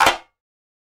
Snare 013.wav